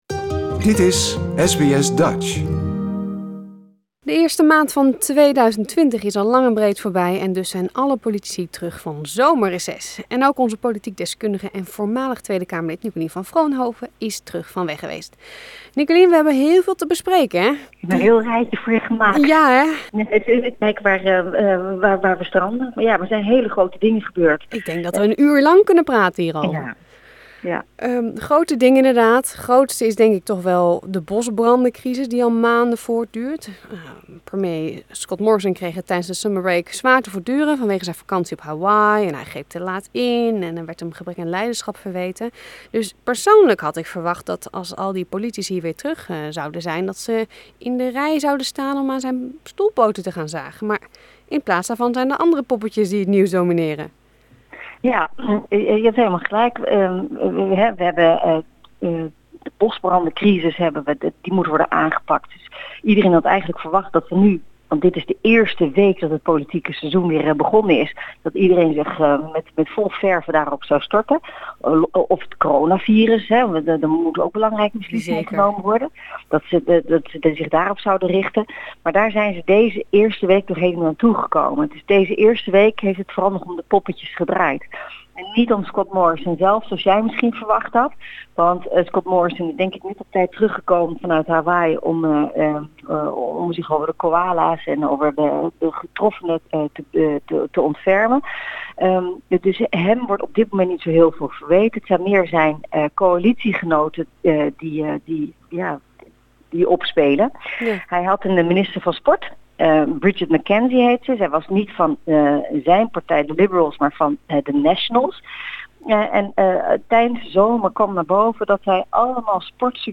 Onze politiek deskundige en voormalig Tweede Kamerlid Nicolien van Vroonhoven bepreekt het wel en wee van de Australische politiek.